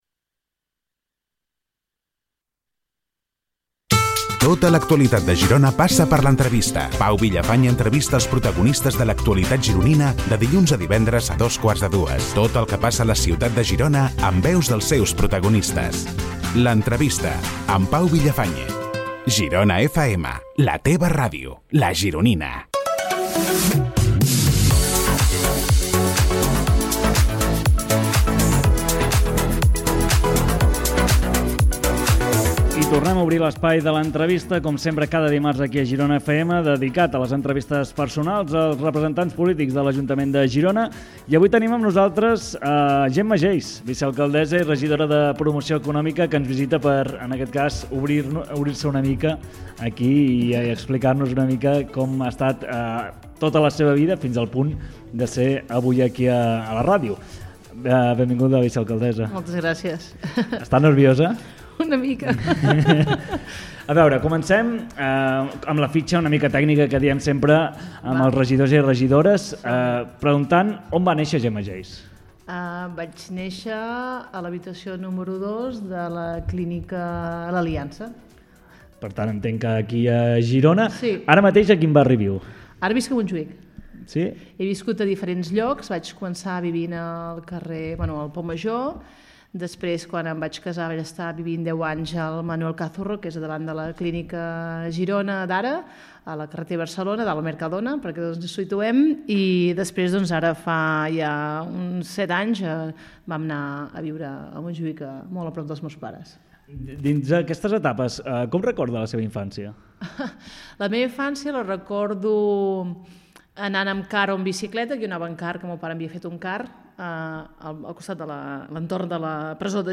Entrevista personal a la Vicealcaldessa i Regidora de Promoció Econòmica Gemma Geis a GironaFM